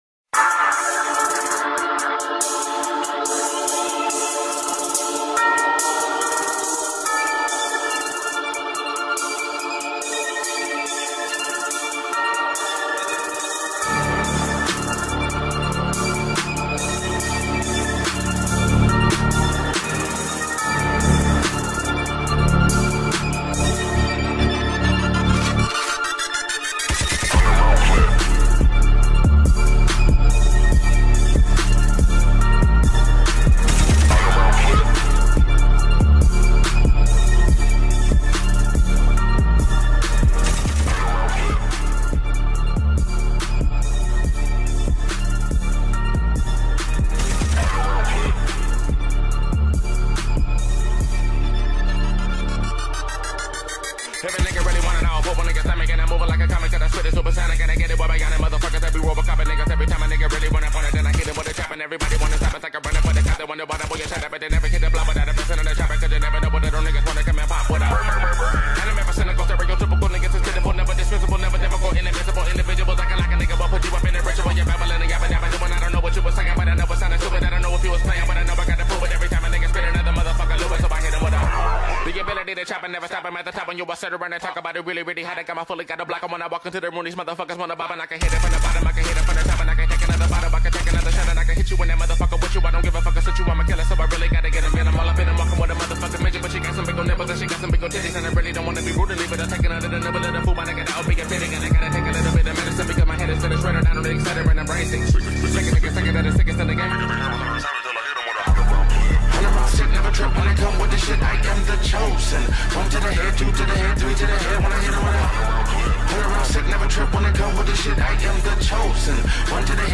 نقربیا از ثانیه ۵۵ شروع به خوندن میشه از دستش ندید